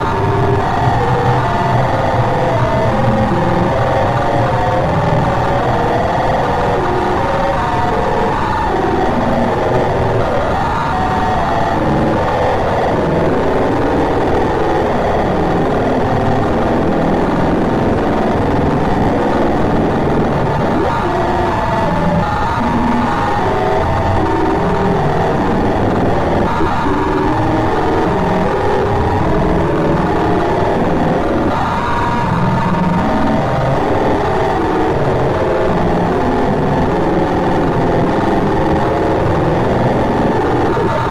Rush_Scream.mp3